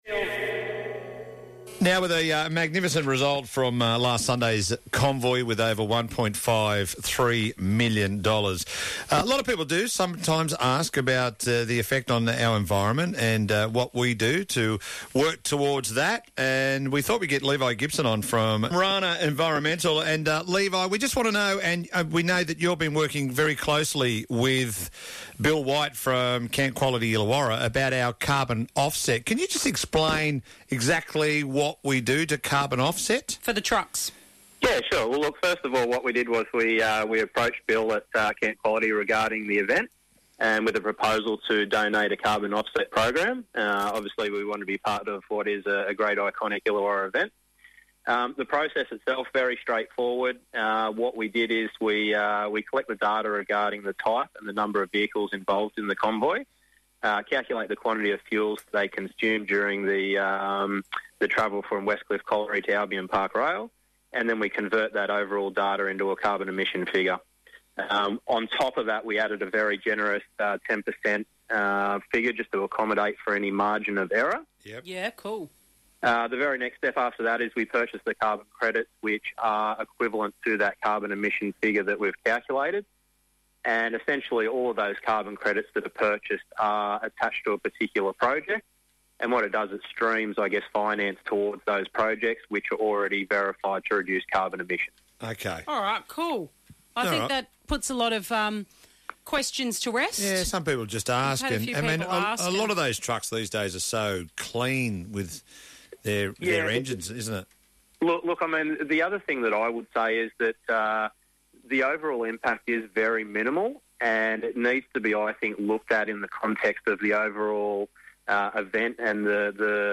i98FM interview.